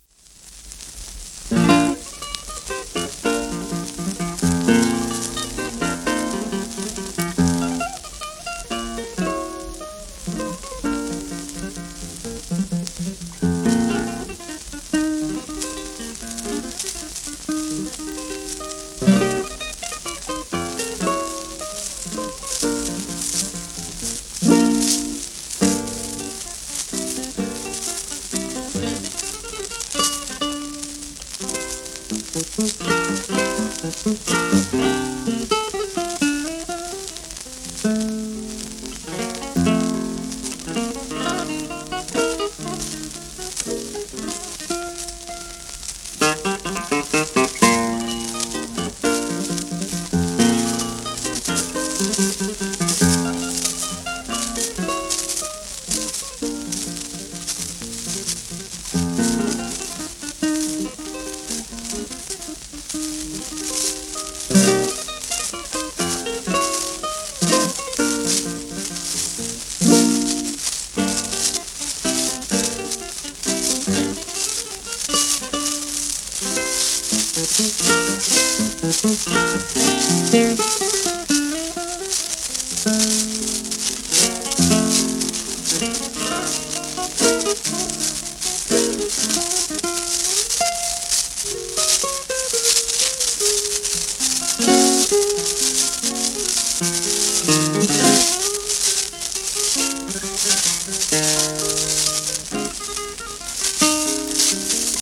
盤質A- *サーフェイスノイズあり
シェルマン アートワークスのSPレコード